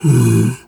bear_roar_soft_05.wav